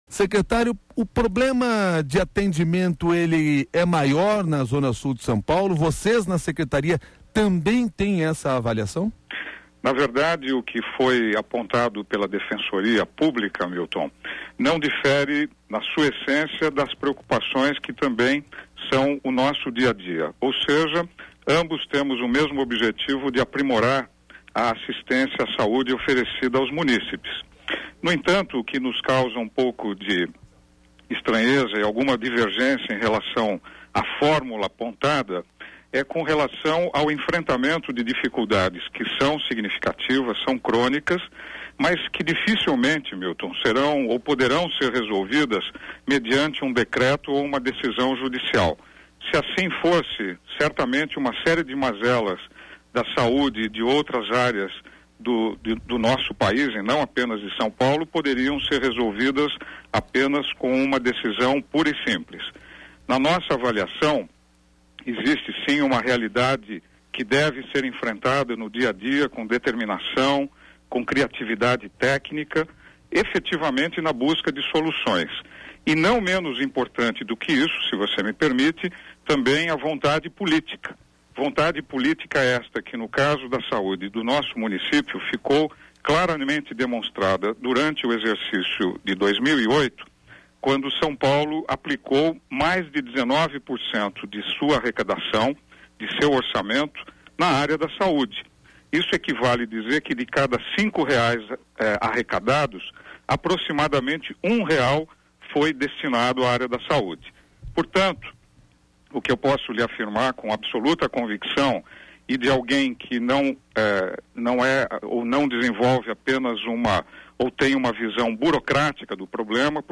Ouça a entrevista do secretário José Maria Orlando